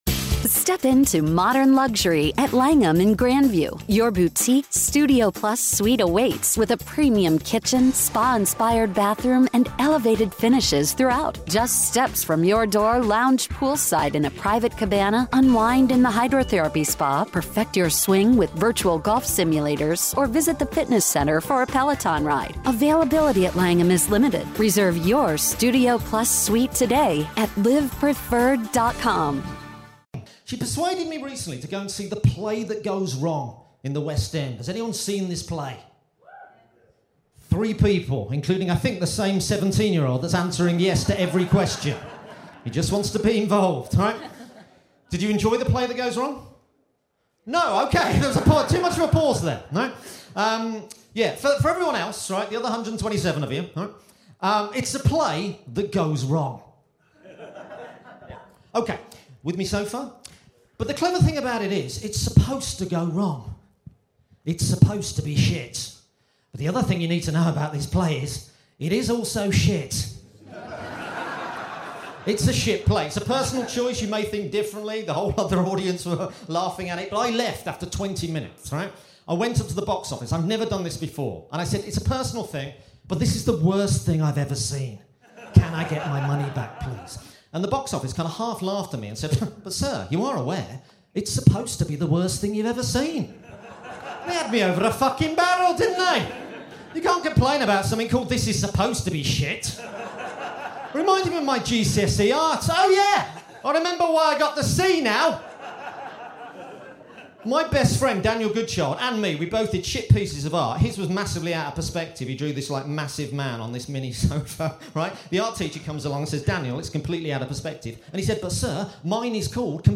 Recorded Live at Edinburgh Fringe.